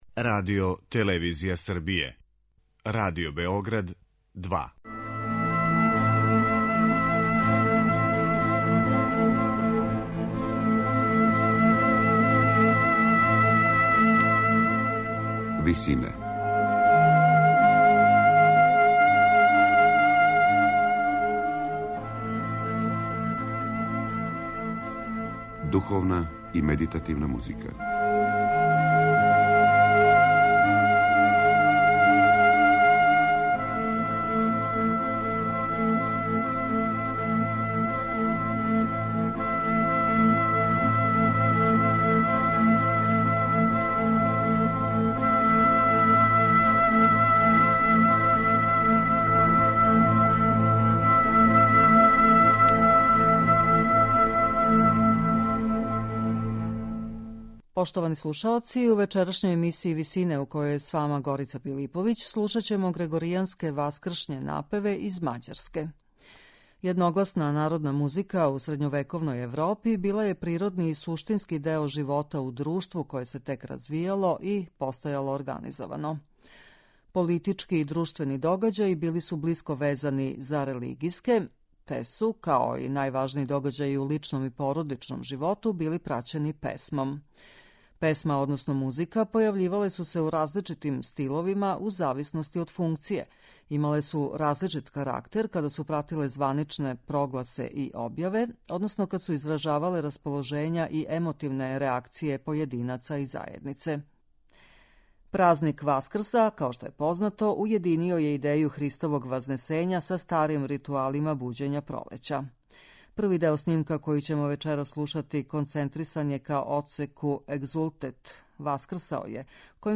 Емисија духовне и медитативе музике
У вечерашњој емисији можете слушати грегоријанске васкршње напеве из Мађарске, у извођењу ансамбла Схола хунгарика.